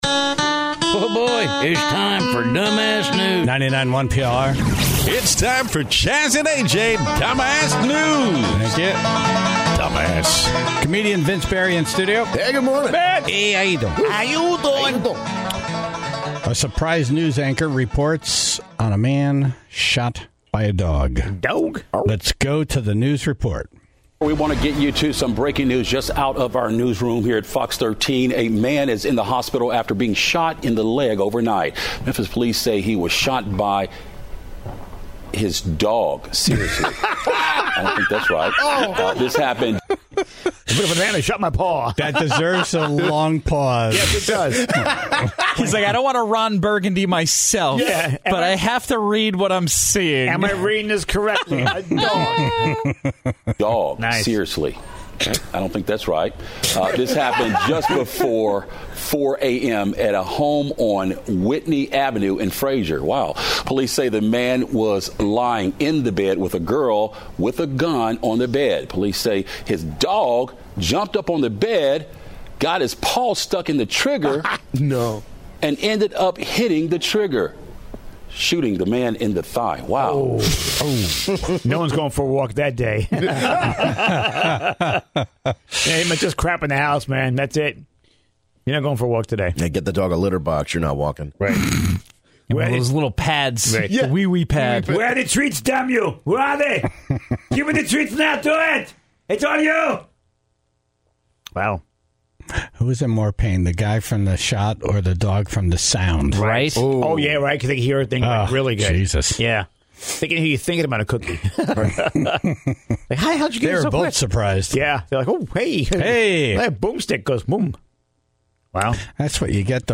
The Tribe called in to share their stories of extreme weather.